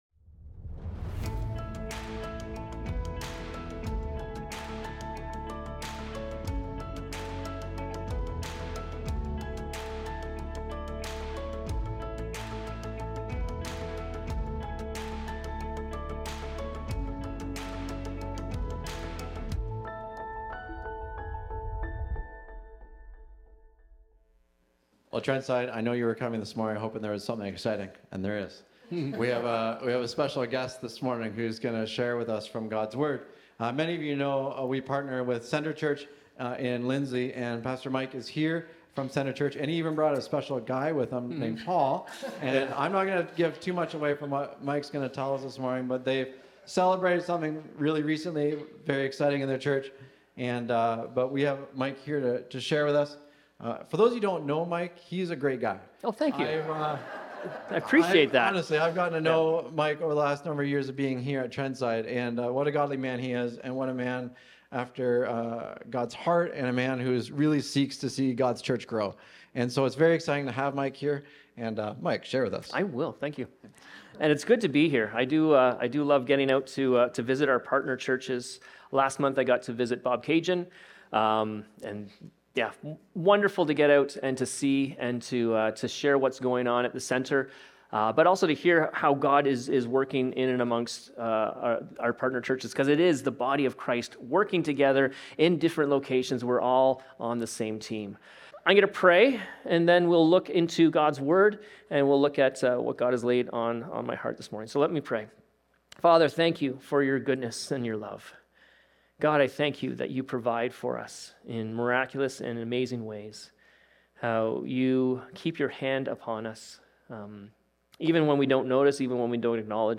Recorded Sunday, April 12, 2026, at Trentside Fenelon Falls.